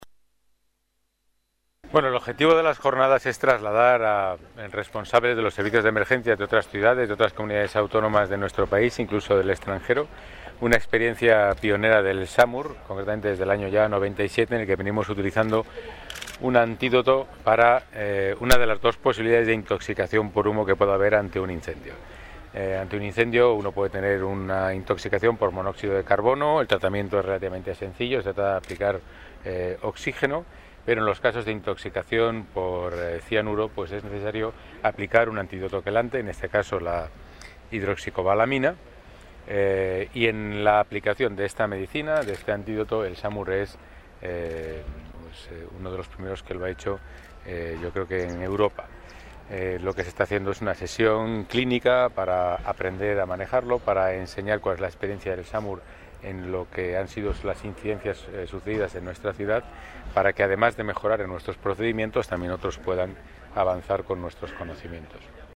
Nueva ventana:Pedro Calvo, concejal de Seguridad: Simulacro Samur